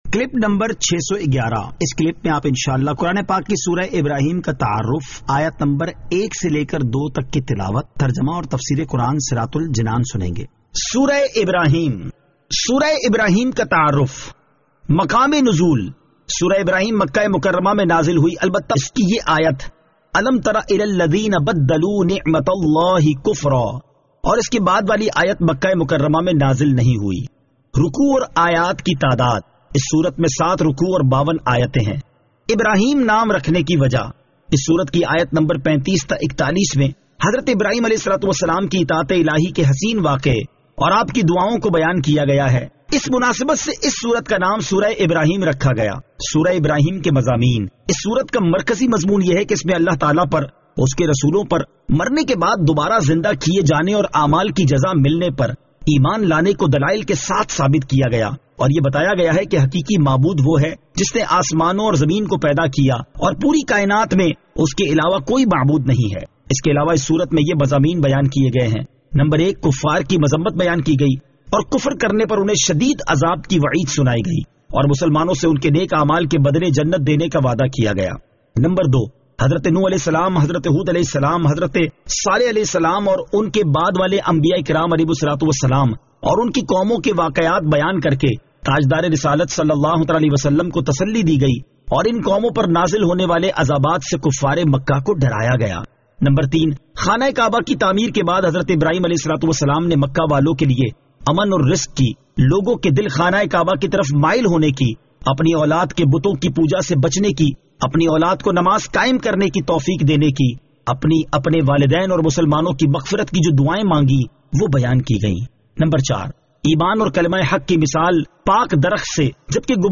Surah Ibrahim Ayat 01 To 02 Tilawat , Tarjama , Tafseer